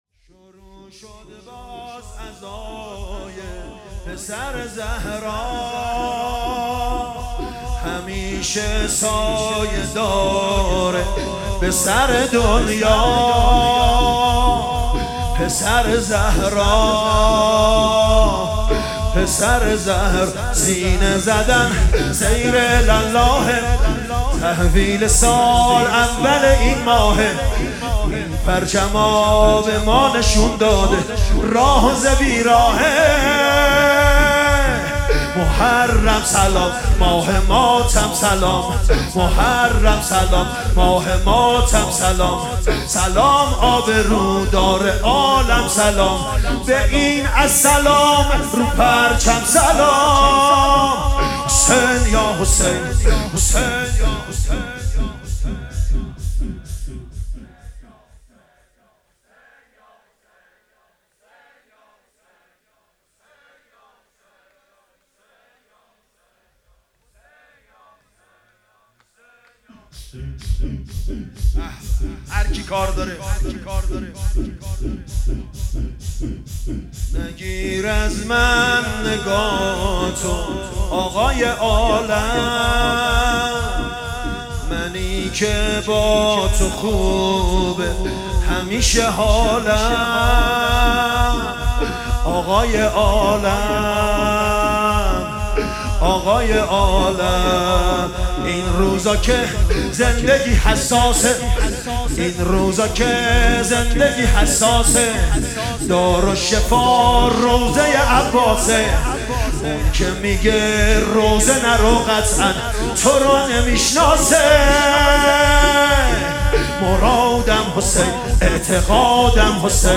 شور پرشد همه جا زمزمه